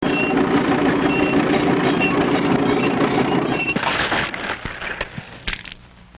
splat.mp3